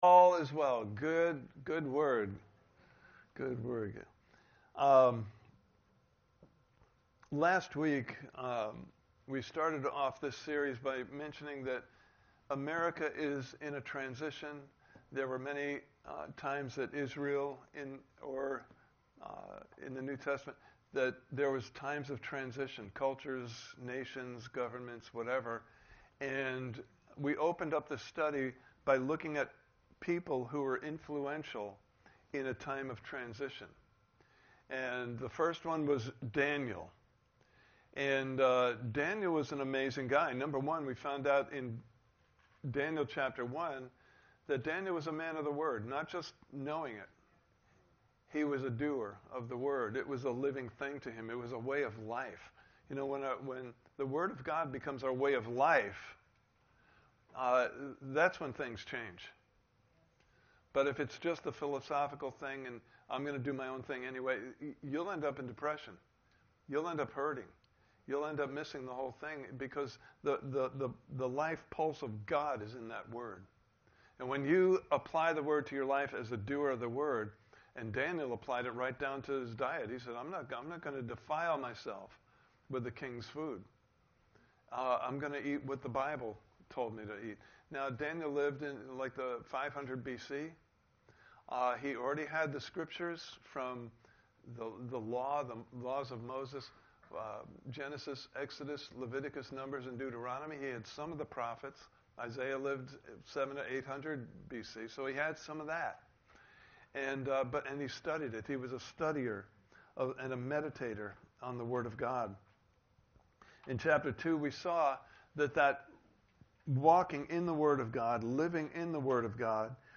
Series: Walking With the One Who Knows the Future Service Type: Sunday Morning Service « Part 1